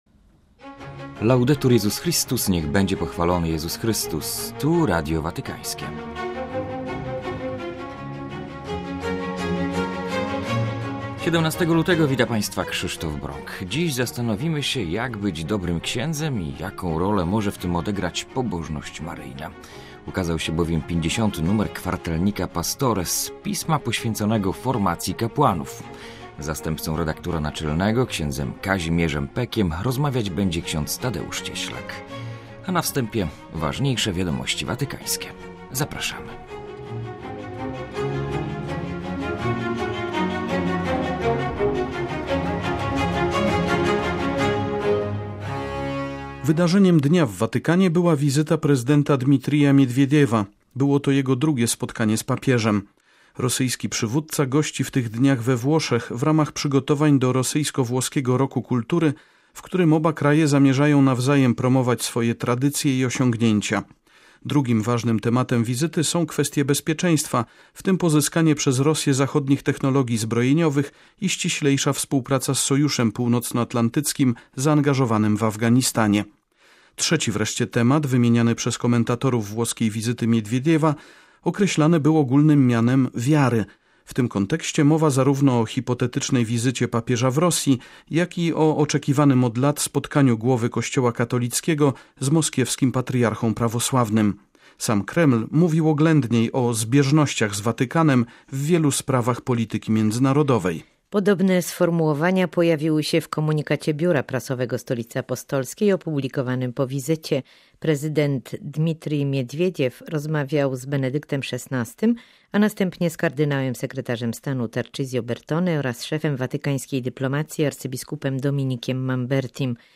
A na wstępie aktualności watykańskie.